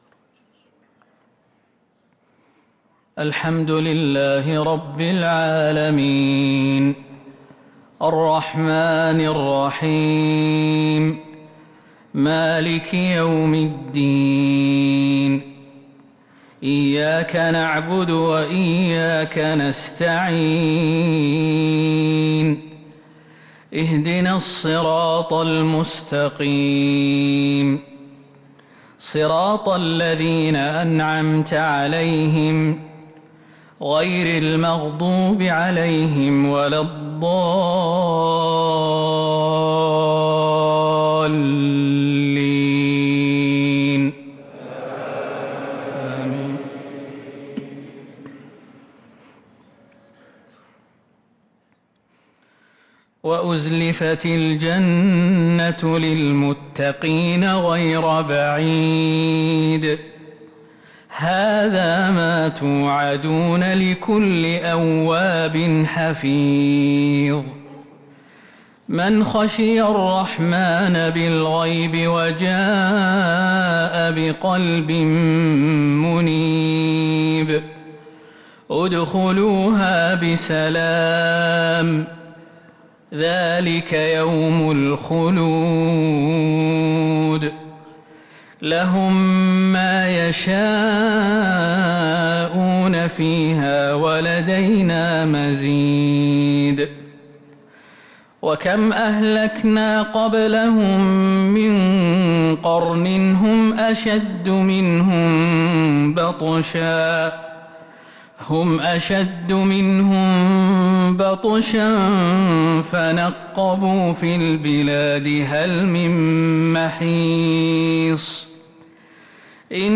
صلاة العشاء للقارئ خالد المهنا 25 شعبان 1444 هـ
تِلَاوَات الْحَرَمَيْن .